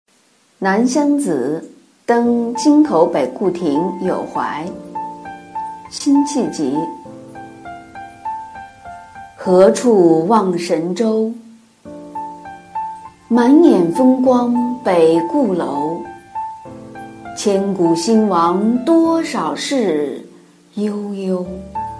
九年级语文下册24诗词曲五首《南乡子·登京口北固亭有怀》女声朗诵（音频素材）